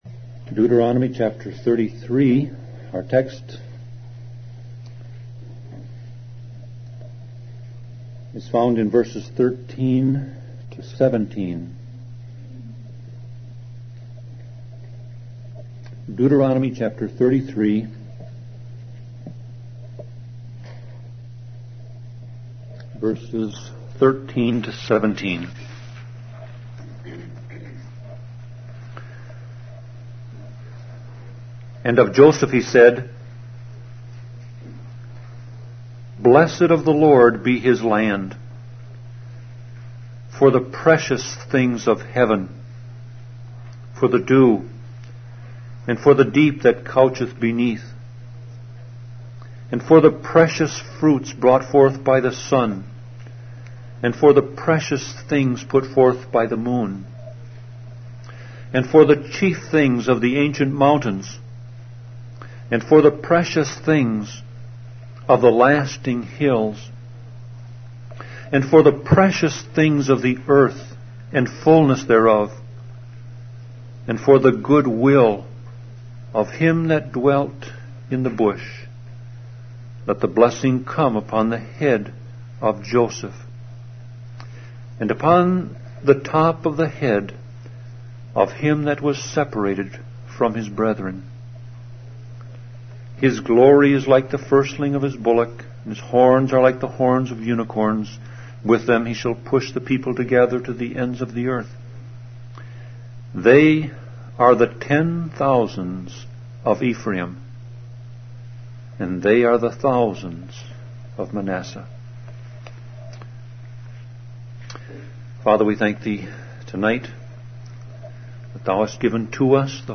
Sermon Audio Passage: Deuteronomy 33:13-17 Service Type